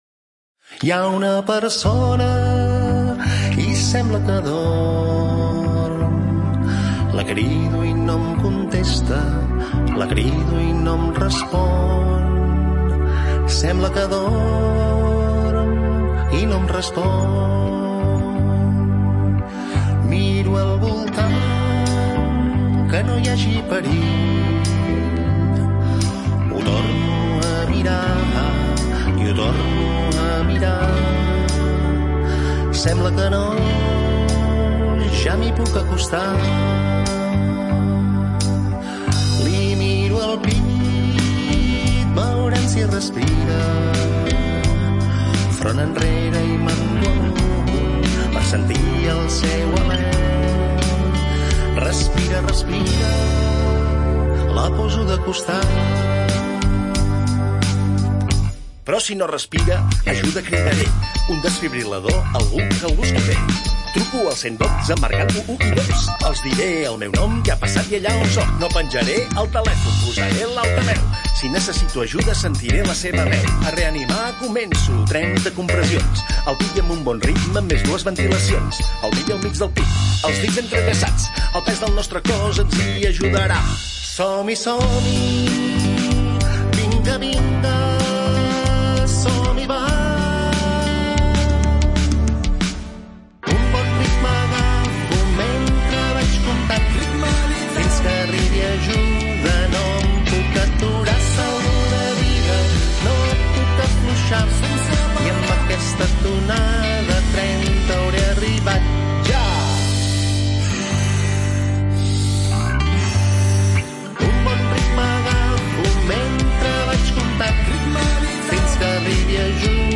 cantautor
Cançó